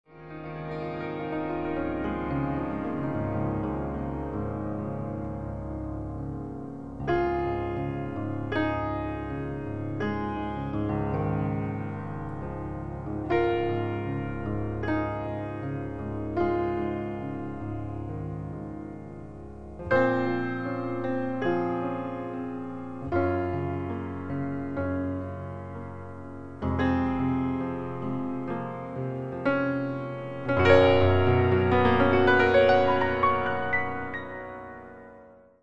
( solo piano )